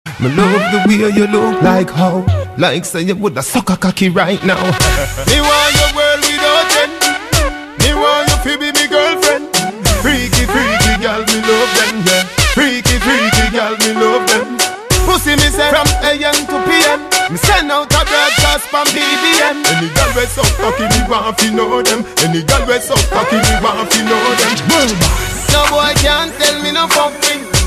M4R铃声, MP3铃声, 欧美歌曲 104 首发日期：2018-05-14 13:11 星期一